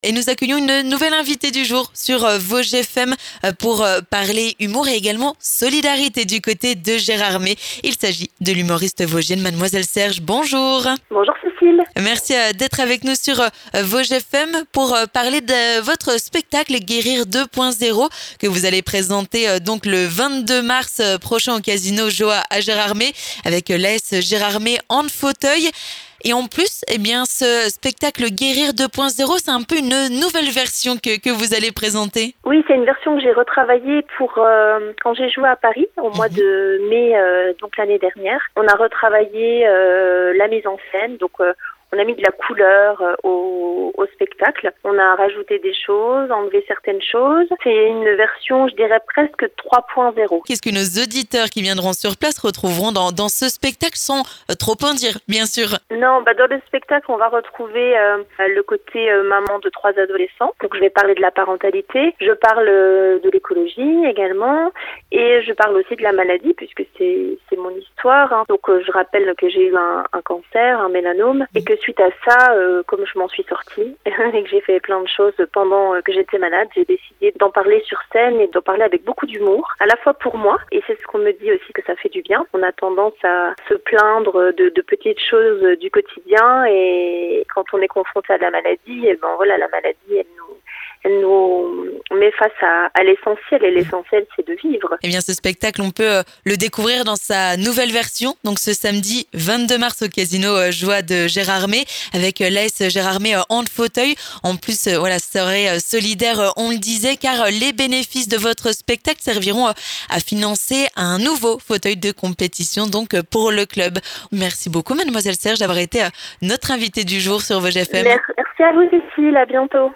Nous partons pour Gérardmer avec notre invitée du jour.